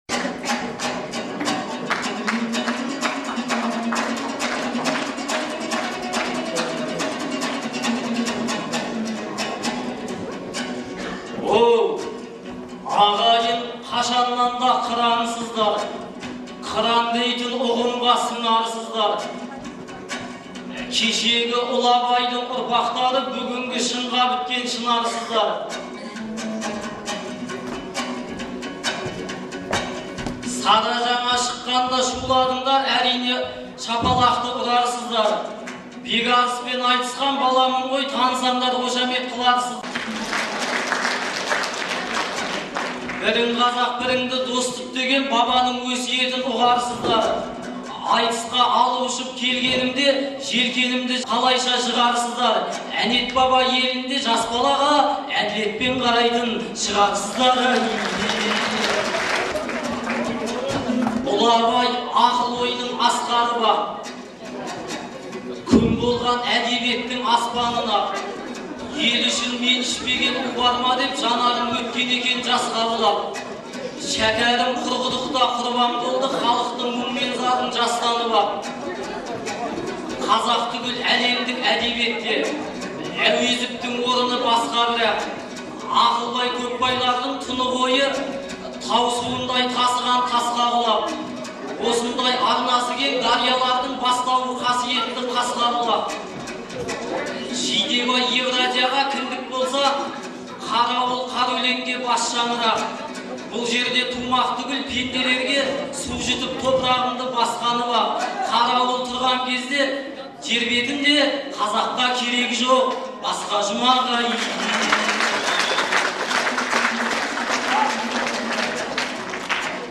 Қарауылдағы айтыс
Қыркүйектің 15-і күні Шығыс Қазақстан облысы Абай ауданының орталығы Қарауылда «Бабалар тойы – ел тойы» деген атпен Көкбай Жанатайұлы мен Ақылбайдың туғанына 150 жыл, Шәкір Әбеновтің туғанына 110 жыл толуына орай республикалық айтыс өтті.